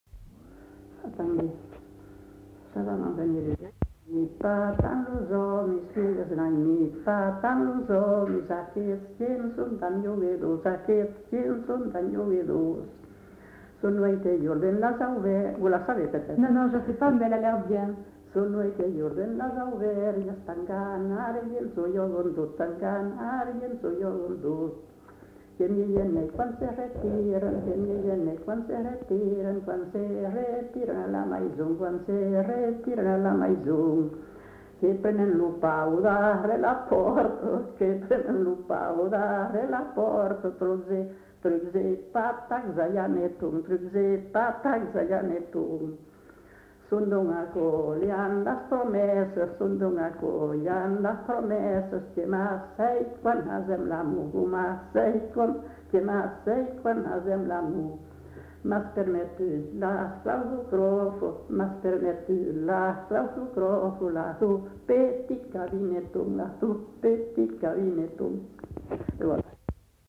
[Brocas. Groupe folklorique] (interprète)
Genre : chant
Effectif : 1
Type de voix : voix de femme
Production du son : chanté